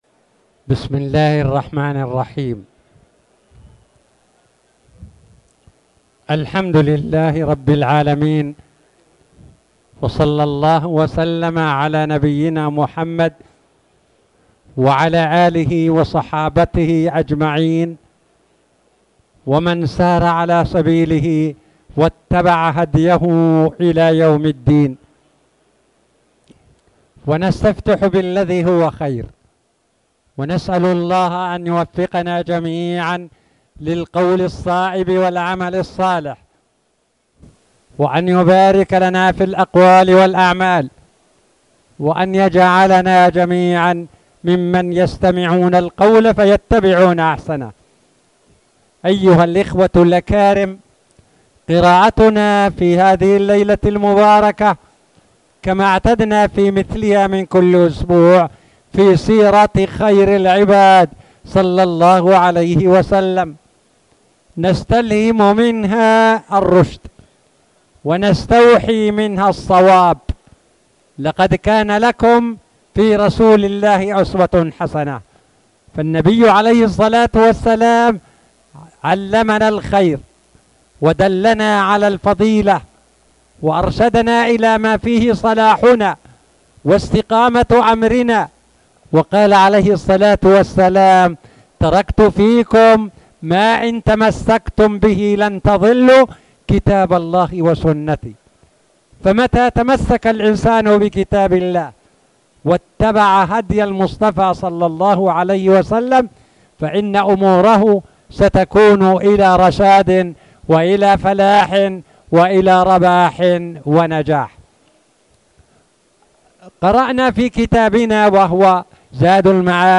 تاريخ النشر ٩ رجب ١٤٣٨ هـ المكان: المسجد الحرام الشيخ